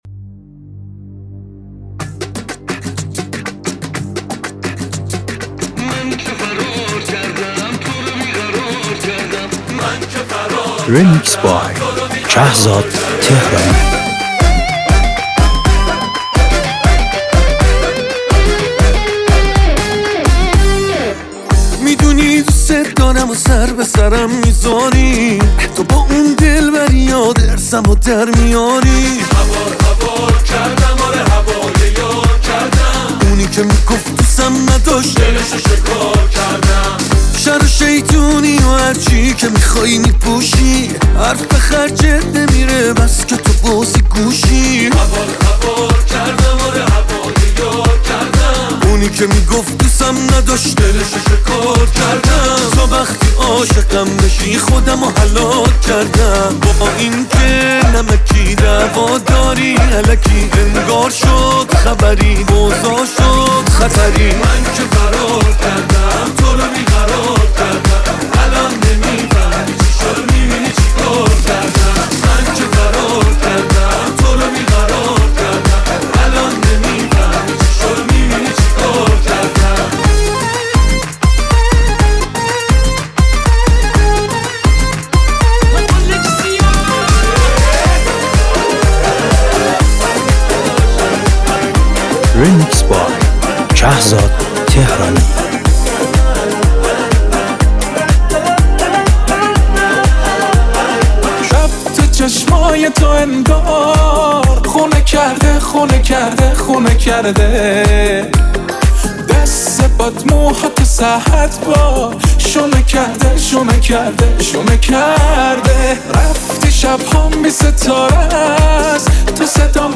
میکس عاشقانه و شاد
مخصوص مهمونی، کافه و دورهمی‌ها.